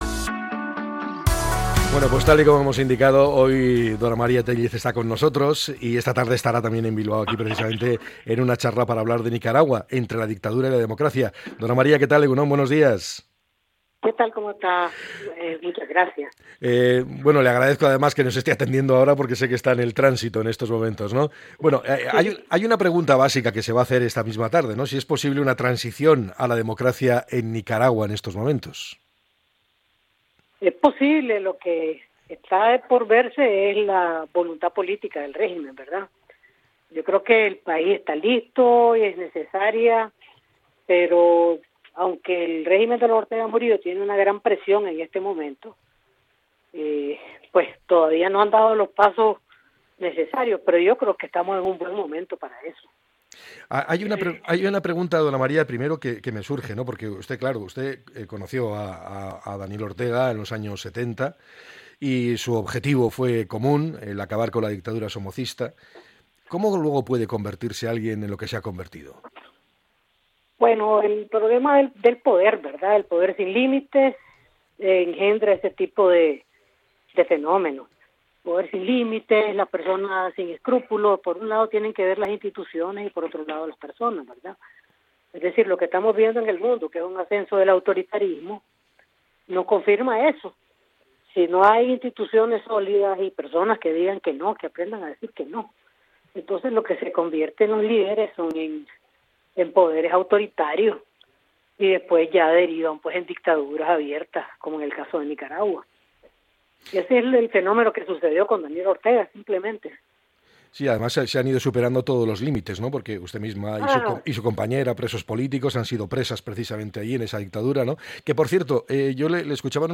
Dora María Téllez, figura histórica de la revolución sandinista y hoy una de las voces más firmes contra la deriva autoritaria del régimen, ha pasado por los micrófonos de EgunOn Bizkaia antes de su conferencia en Bilbao.